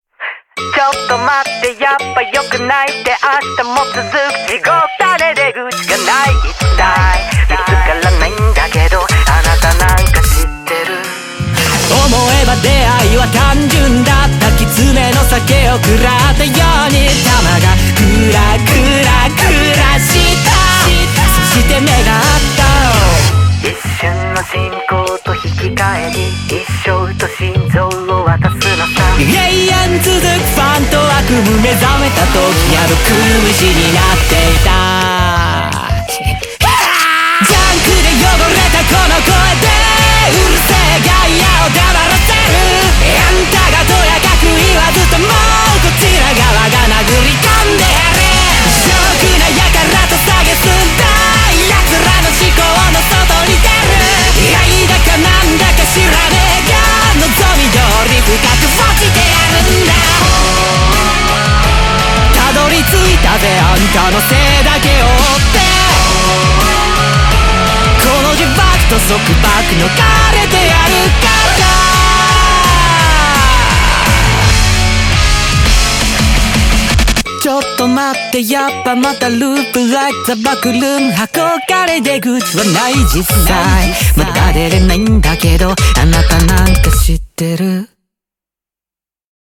BPM85-169
Audio QualityPerfect (Low Quality)